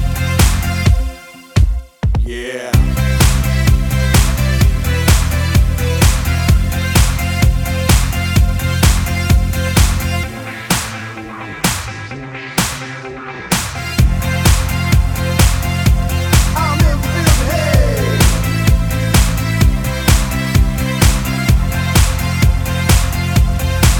For Solo Male Dance 3:53 Buy £1.50